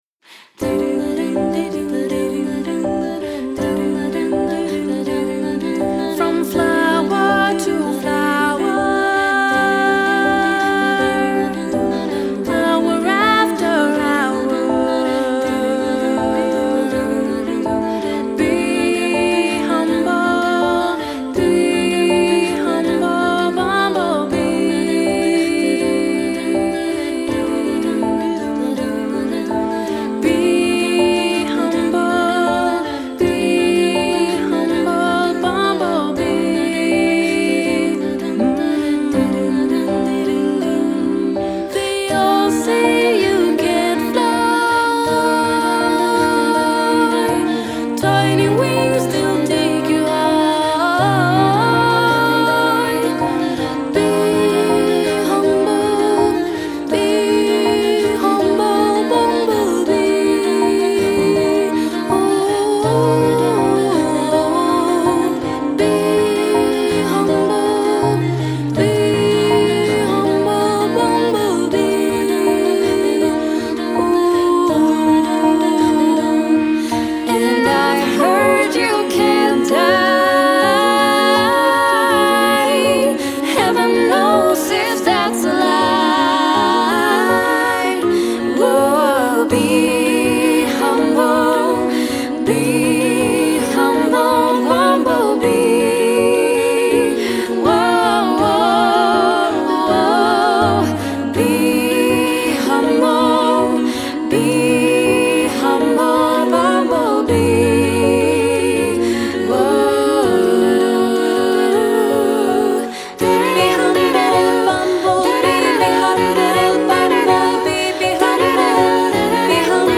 Voicing: SSAATB